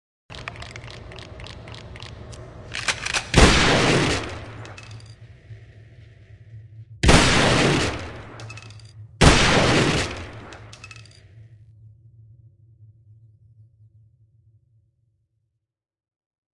Calibre 9的声音 " FUSIL
描述：大枪射击重装
Tag: 重装 射击